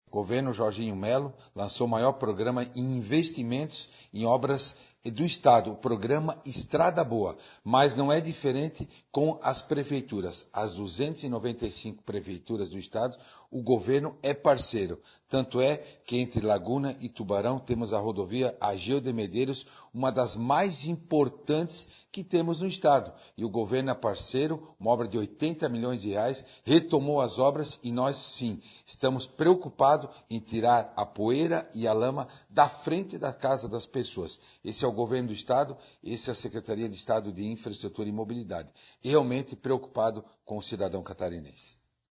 SECOM-Sonora-secretario-da-Infraestrutura-e-Mobilidade.mp3